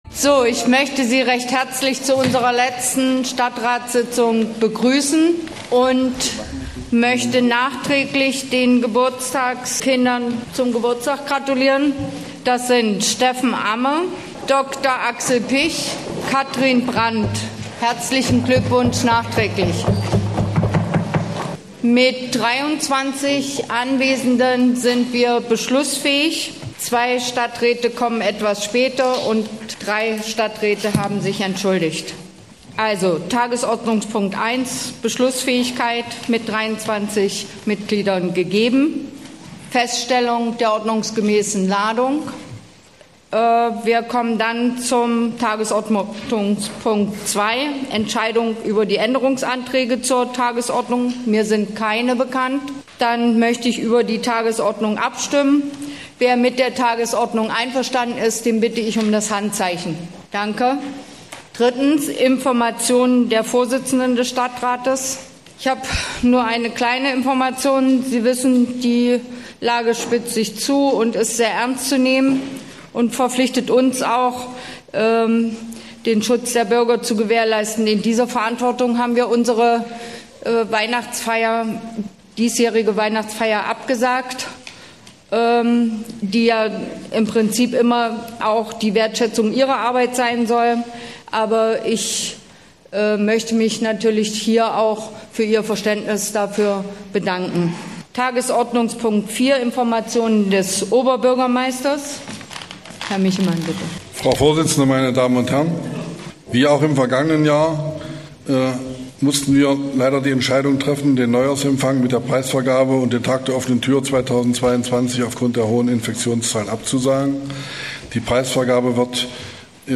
Stadtratssitzung in Aschersleben
Der Ascherslebener Stadtrat hat wieder im Bestehornhaus getagt. Diesmal ging es zum Beispiel um den Haushalt für 2022, um die Wirtschaftspläne der städtischen Gesellschaften, um die Friedhofsgebühren und um die Schulentwicklungsplanung.